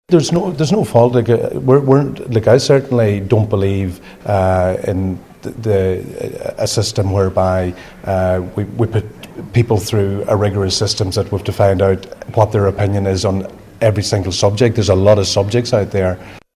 The Education Minister says Murphy was selected fair and square by party membership: